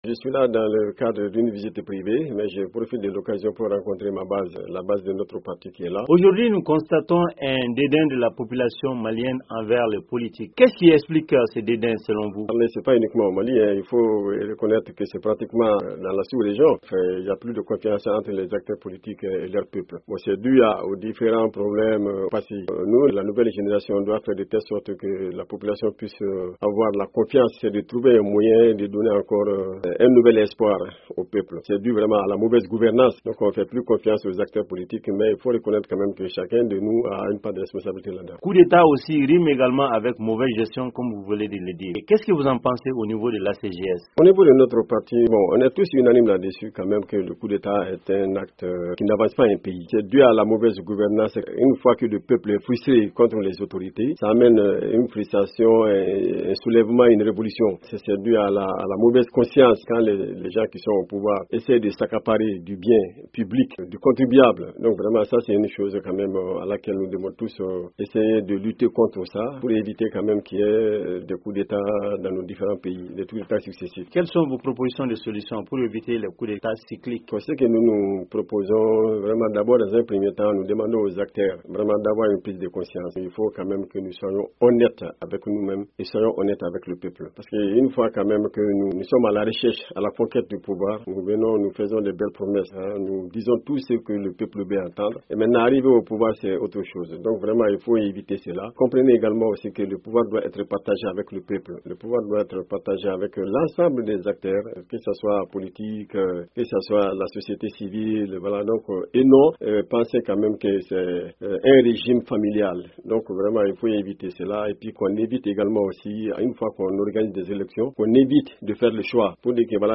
dans le studio VOA